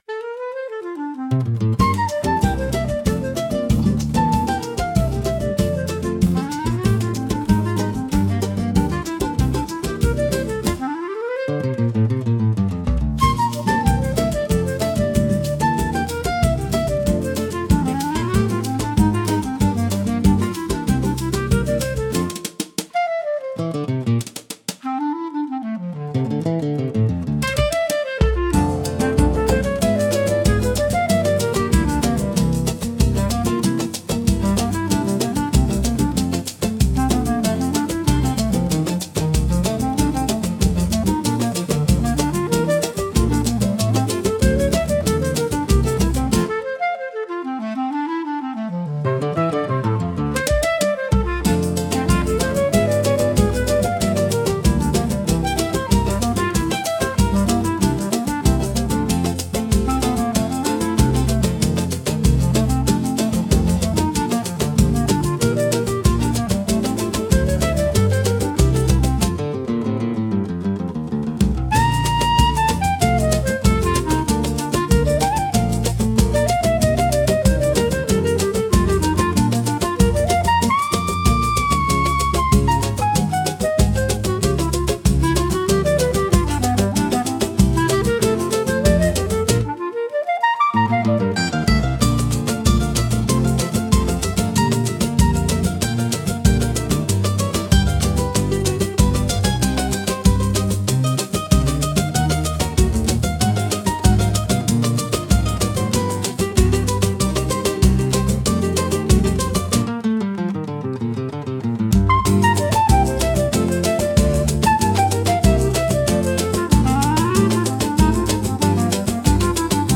música e arranjo: IA) instrumental 8